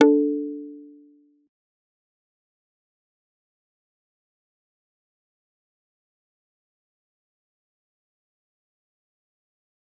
G_Kalimba-D4-pp.wav